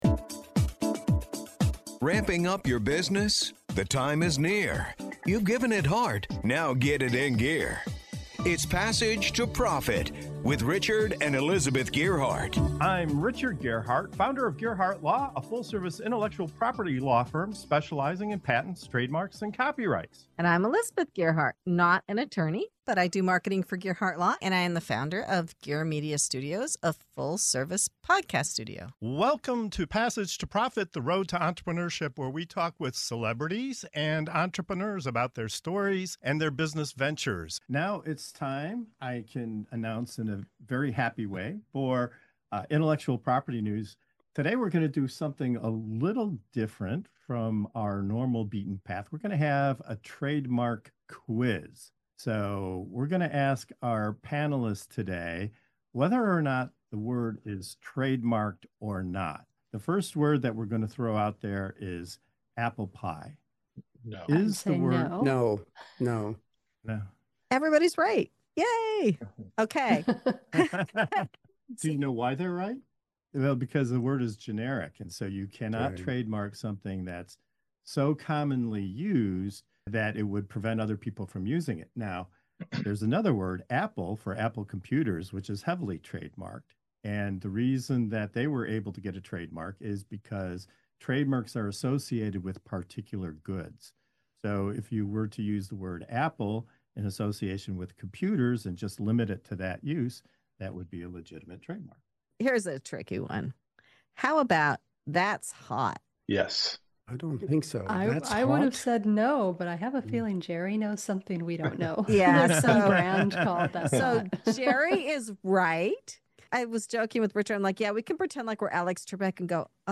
In this playful, game-show-style segment of "IP News" on Passage to Profit Show, our panelists take on a rapid-fire quiz to guess which words and phrases are protected — from “apple pie” to “That’s Hot” to “superhero.” Along the way, you’ll uncover surprising trademark rules, quirky legal loopholes, and the odd ways companies lock down their brands.